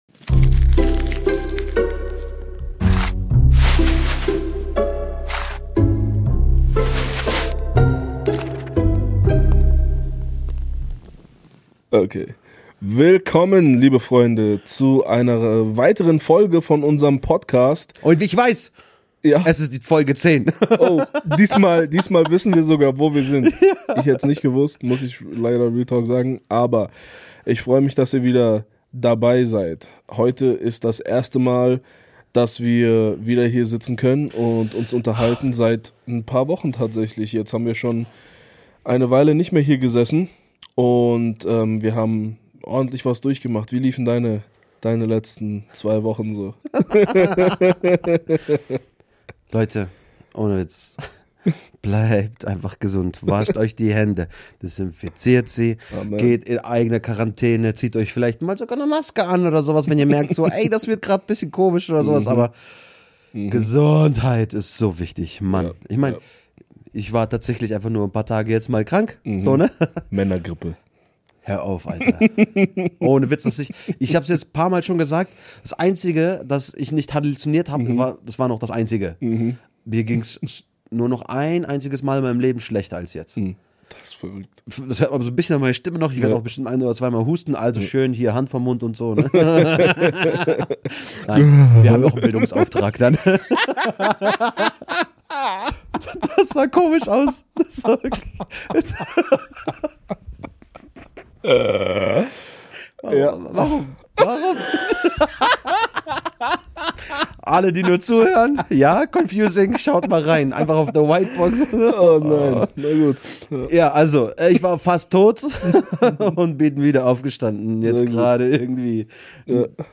In dieser Episode sprechen wir über Lügen, Gewissen und schwierige Dilemmata. Mit christlicher Perspektive fragen wir: Wie können wir so handeln, dass es Gott und den Menschen gut tut? Ein ehrliches Gespräch über Werte, Verantwortung und Wachstum.